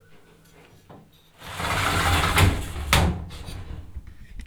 Cierre de puertas metálicas
Grabación sonora del cierre de unas puertas metálicas
Sonidos: Acciones humanas